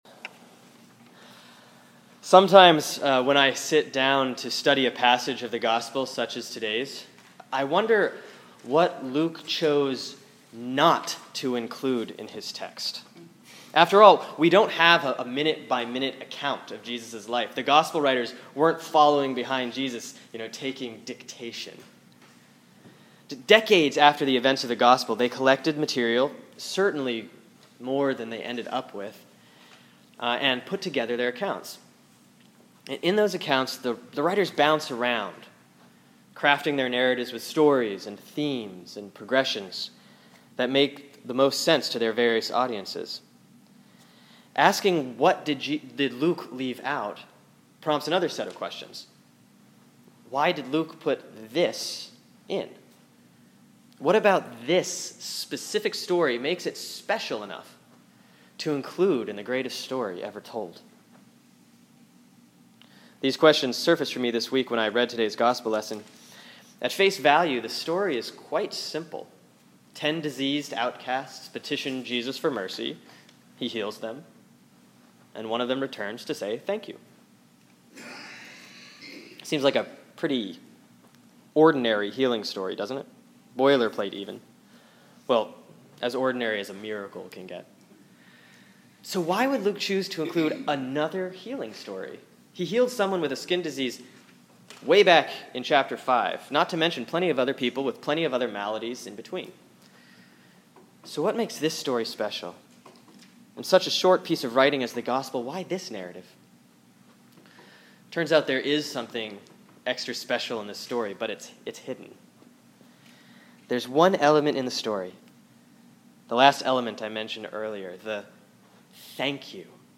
(Sermon for Sunday, October 13, 2013 || Proper 23C || Luke 17:11-19)